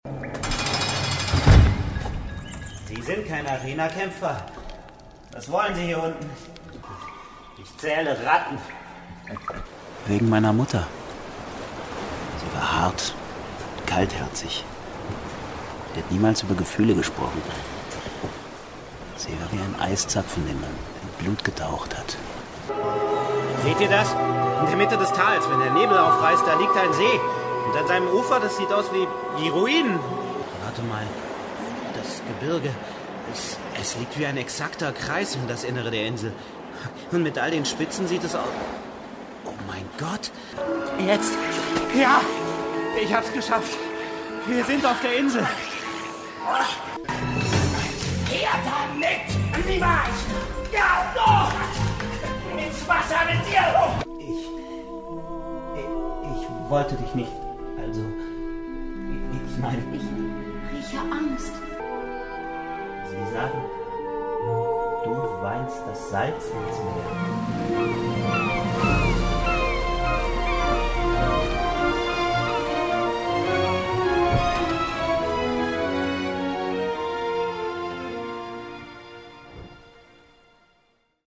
markant, sehr variabel
Jung (18-30)
Audio Drama (Hörspiel)